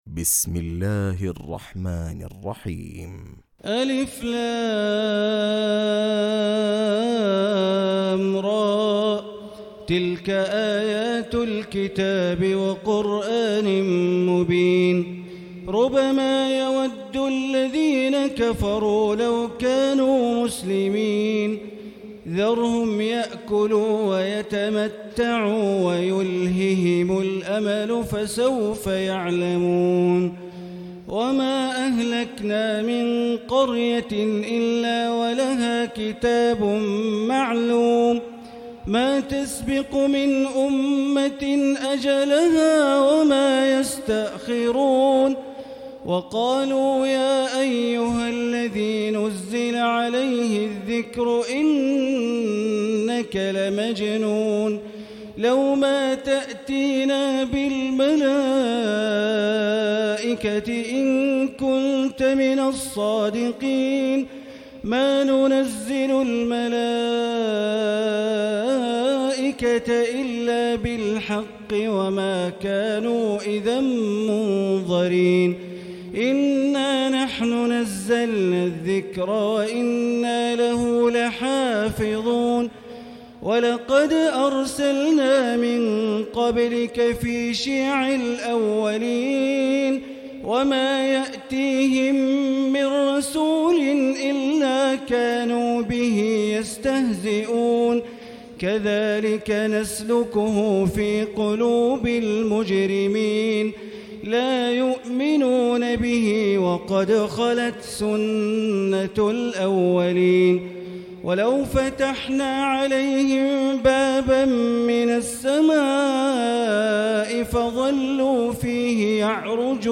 تراويح الليلة الثالثة عشر رمضان 1439هـ من سورتي الحجر كاملة و النحل (1-52) Taraweeh 13 st night Ramadan 1439H from Surah Al-Hijr and An-Nahl > تراويح الحرم المكي عام 1439 🕋 > التراويح - تلاوات الحرمين